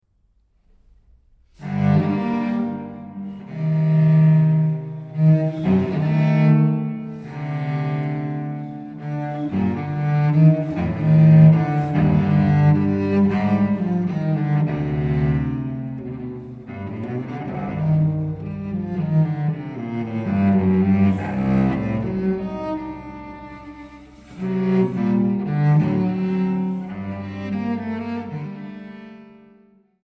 Transkiptionen für Violoncello